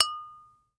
GLASS_Tap_mono.wav